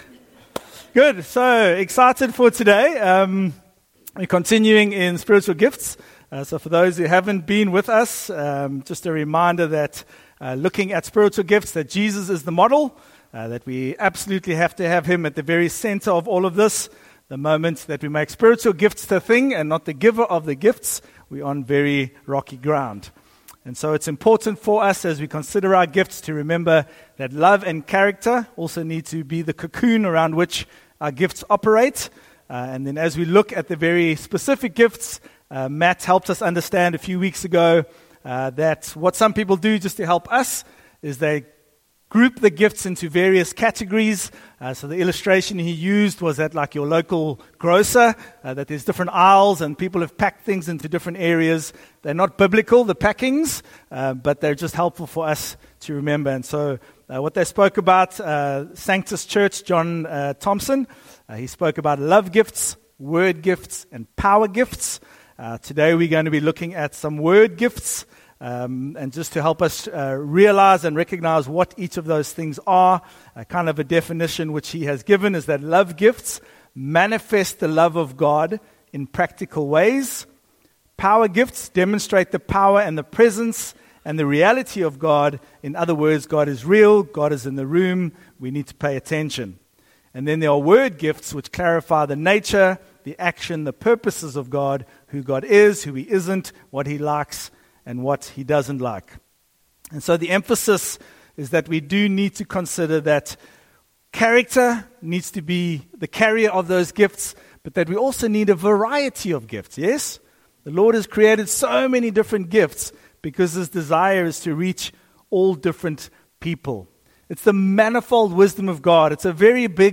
Sanctus Church Podcast Evening Service